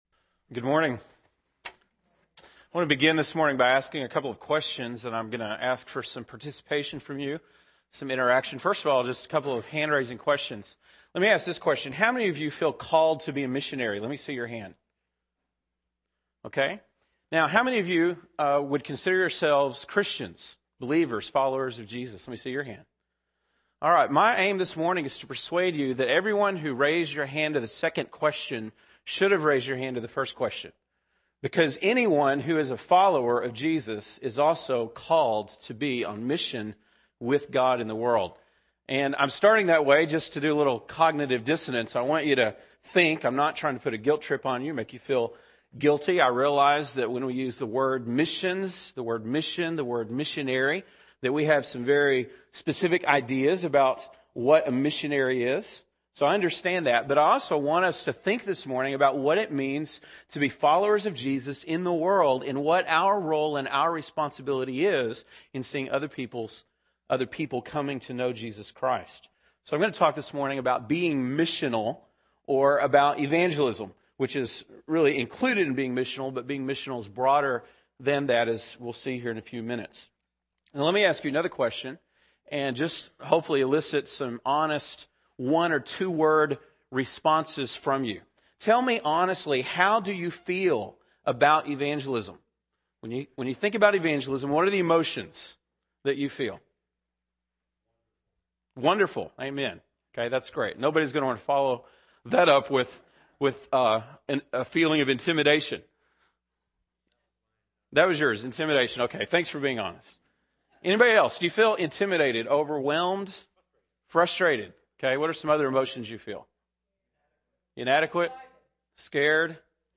January 27, 2008 (Sunday Morning)